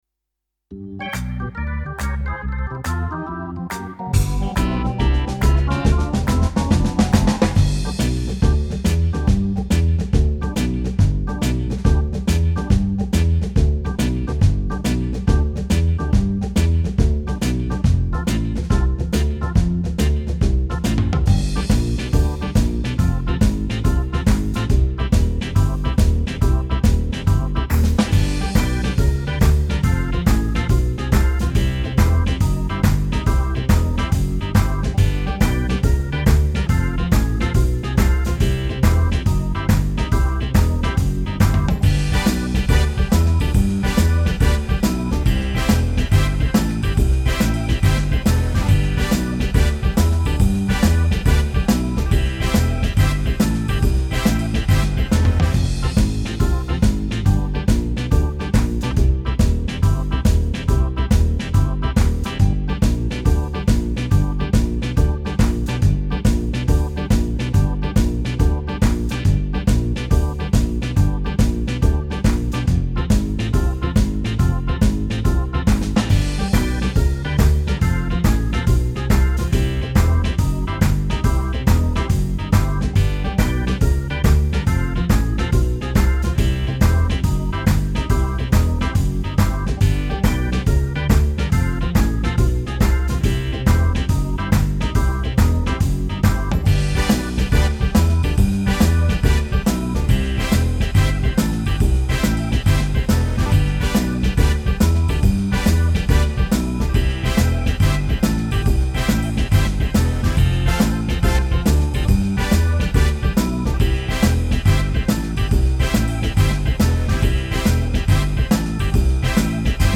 Home > Music > Jazz > Bright > Laid Back > Running